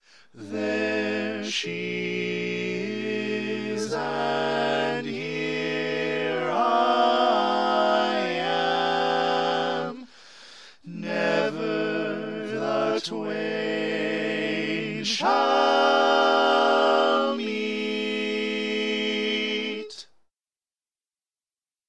Key written in: A Minor
Type: Barbershop